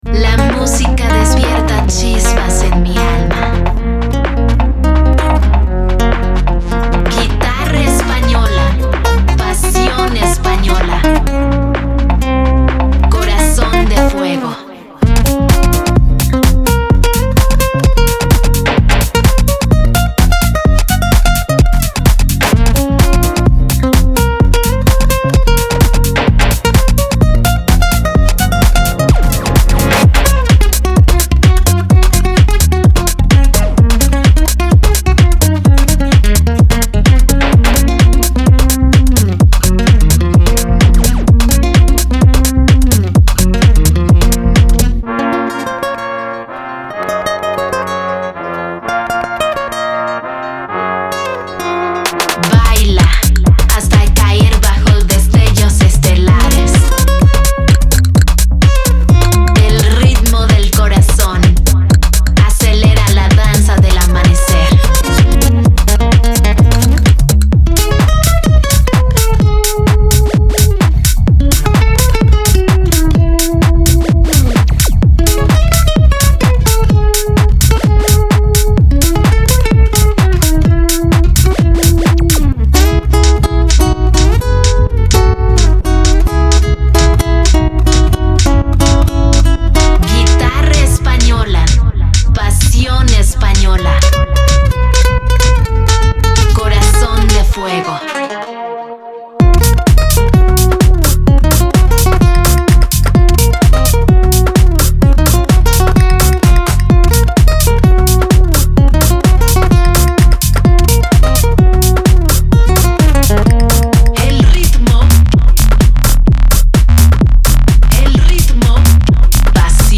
Genre:Tech House
スペインギター、キャッチーな女性ボーカル、そして体を揺らすグルーヴが満載です。
デモサウンドはコチラ↓
125, 128 BPM
48 Spanish Vocals (24 Dry, 24 Wet)
86 Guitars (42 Dry, 44 Wet)